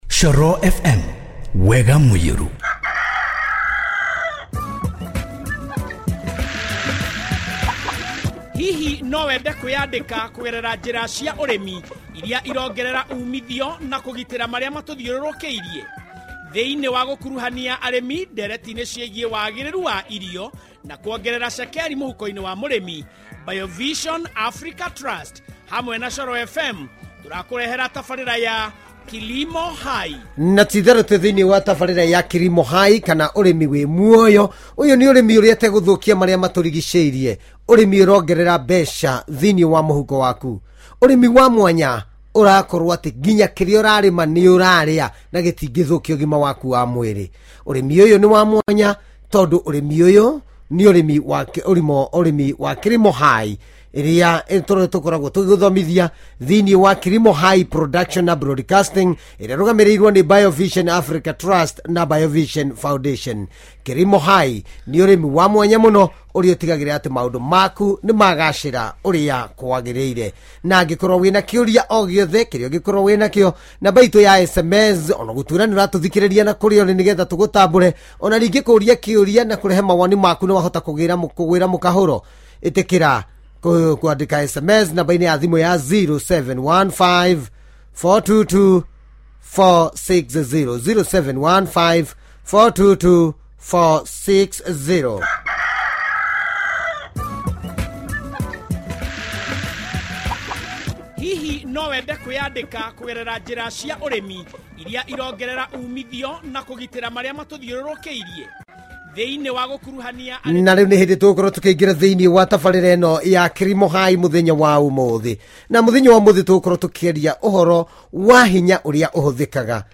Renewable Energy In this studio interview